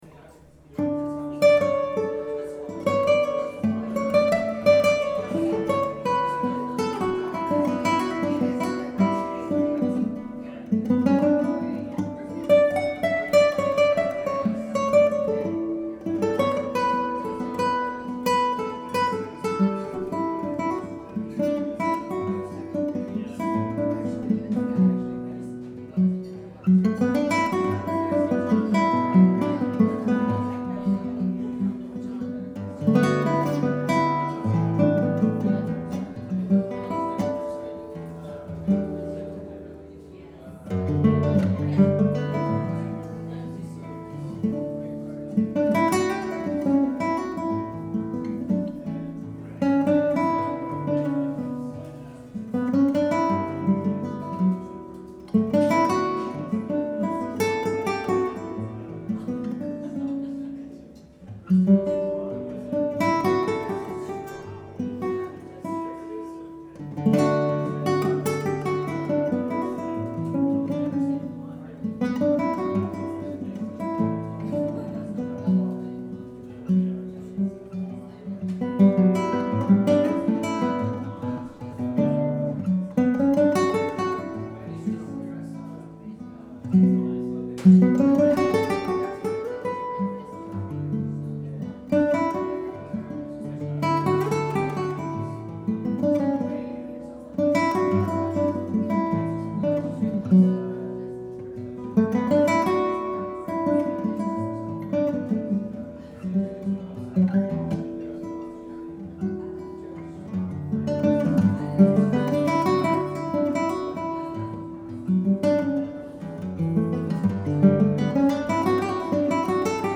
composer/guitar.